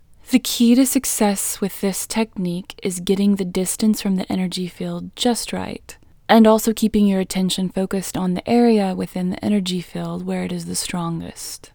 IN Technique First Way – Female English 7